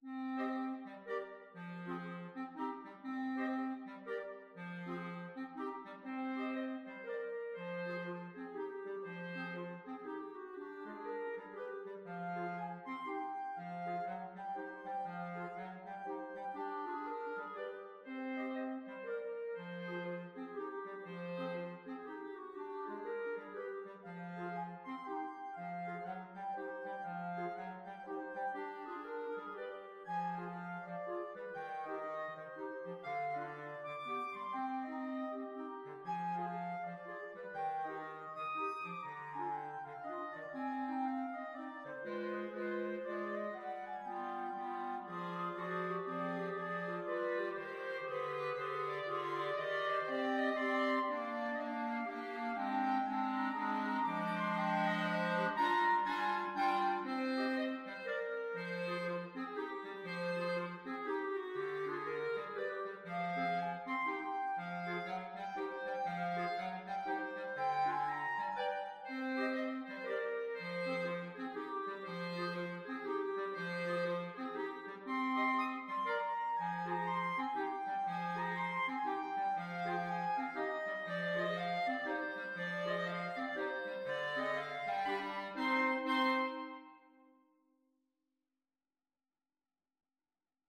3/4 (View more 3/4 Music)
Jazz (View more Jazz Clarinet Quartet Music)